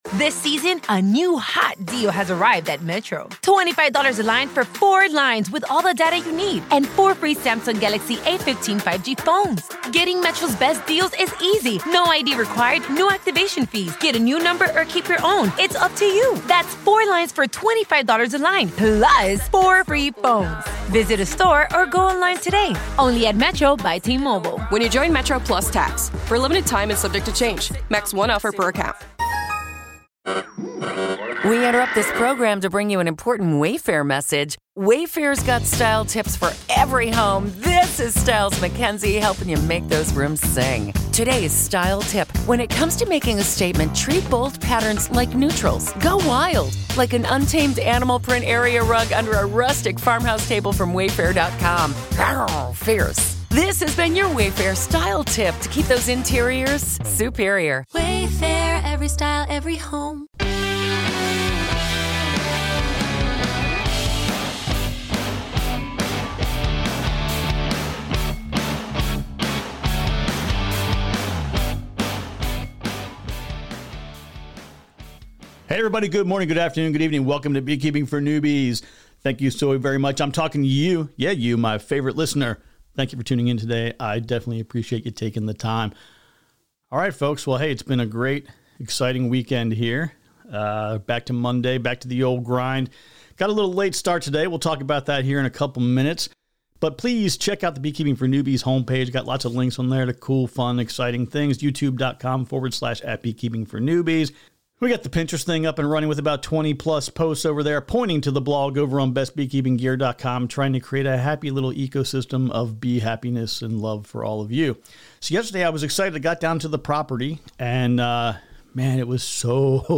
This episode ends with a Q&A segment.